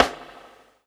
Tennis Snare.wav